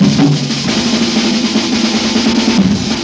JAZZ BREAK 8.wav